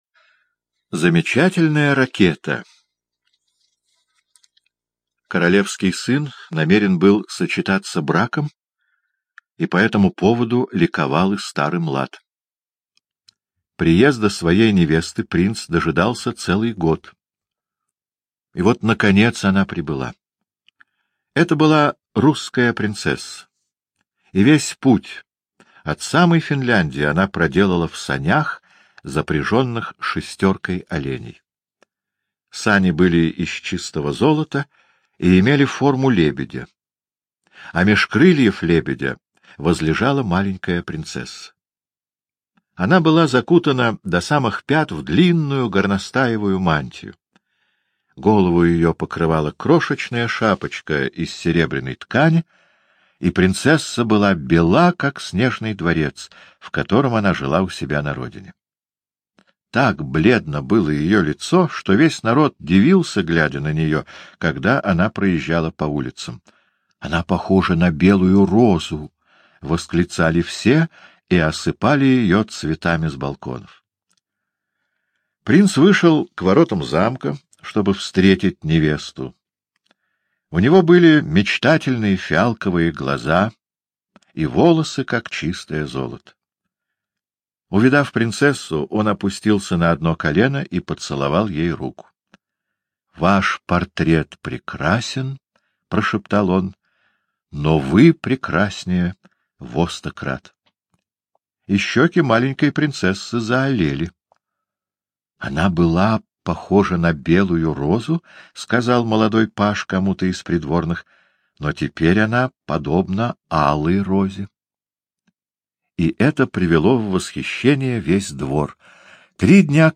Слушайте Замечательная ракета — аудиосказку Уайльда О. Диалог фейерверков, шутих, ракет и огней, который случился на свадьбе принца.